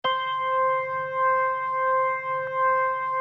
B3LESLIE C 6.wav